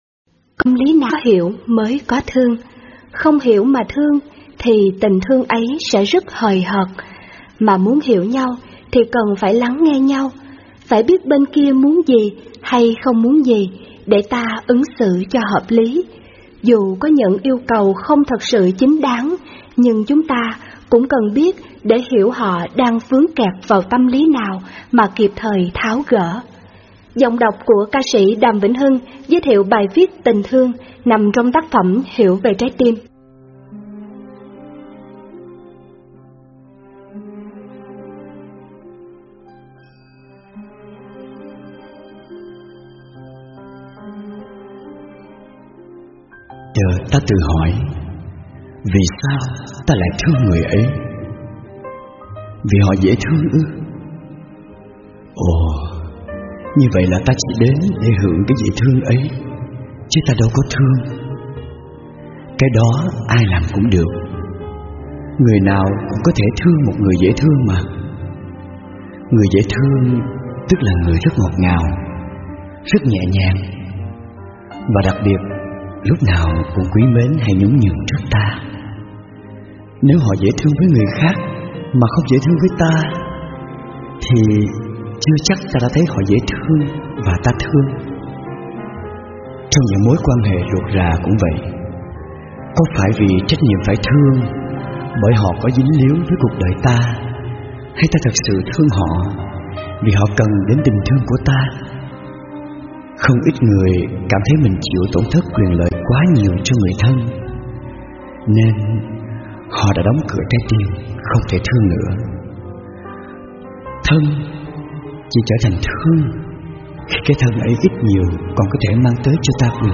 Mời quý vị nghe mp3 sách nói Hiểu Về Trái Tim (Phần 4: Tình Thương) - tác giả ĐĐ. Thích Minh Niệm với giọng đọc CS. Đàm Vĩnh Hưng, hiểu sâu sắc hơn về tình thương yêu trong cuộc sống.